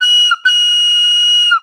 ihob/Assets/Extensions/CartoonGamesSoundEffects/Train_v1/Train_v1_wav.wav at master
Train_v1_wav.wav